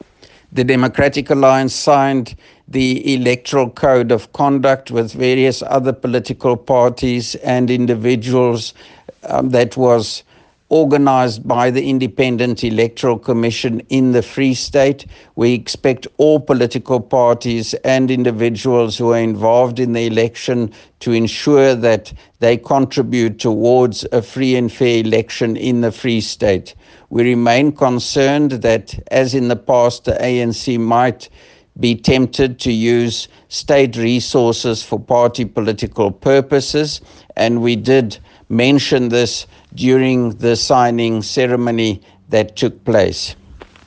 Note to Editors: The following speech was delivered today by the DA Free State Premier Candidate, Roy Jankielsohn, at the IEC signing ceremony in the Free State.
English and Afrikaans soundbites by Roy Jankielsohn MPL.